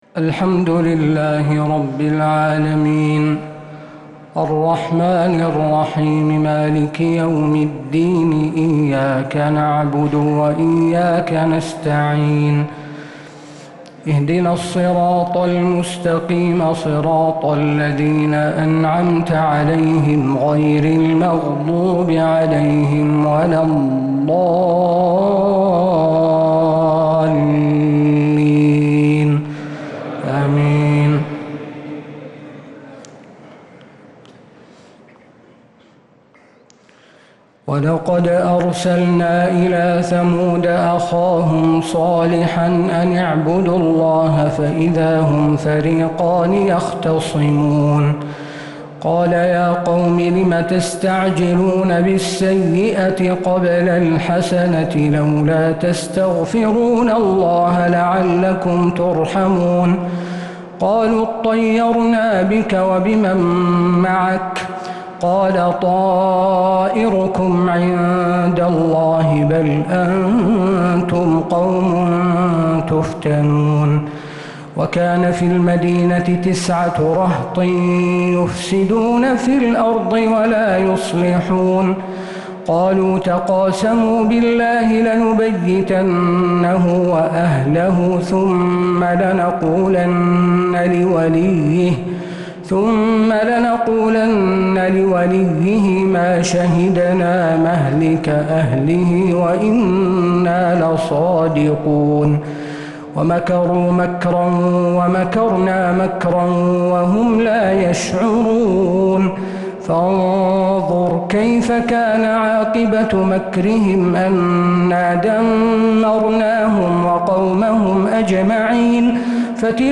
تراويح ليلة 23 رمضان 1446هـ من سورتي النمل (45-93) و القصص (1-13) | taraweeh 23rd night Ramadan 1446H Surah An-Naml and Al-Qasas > تراويح الحرم النبوي عام 1446 🕌 > التراويح - تلاوات الحرمين